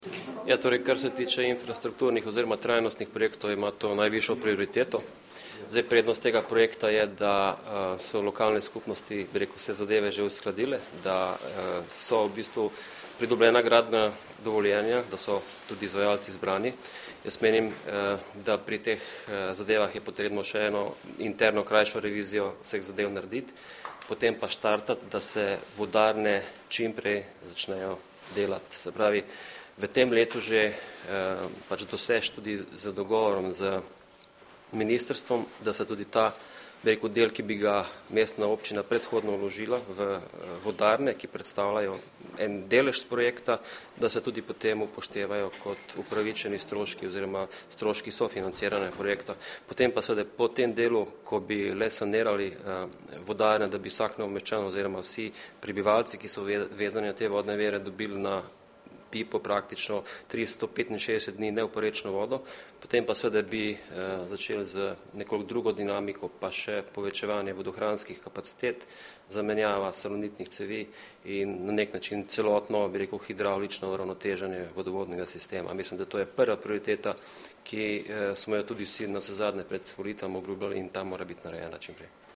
Podžupan Boštjan Grobler o optimizaciji mestnega potniškega prometa